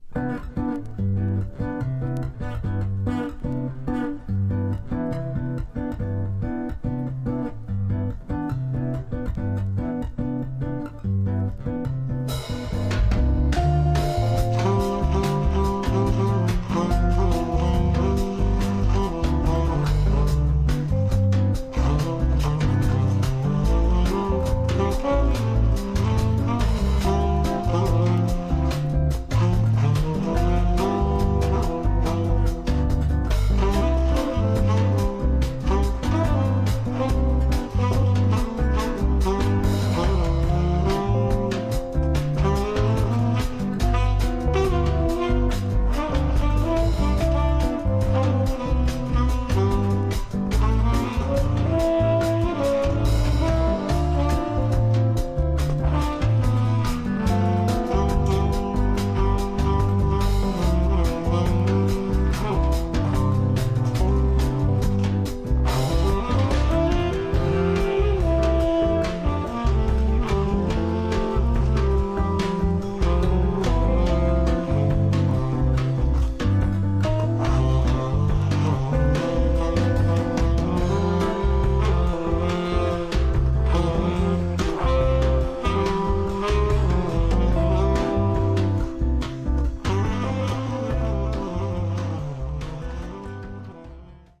Tags: Japan , Cumbia
Deadly cumbia reggae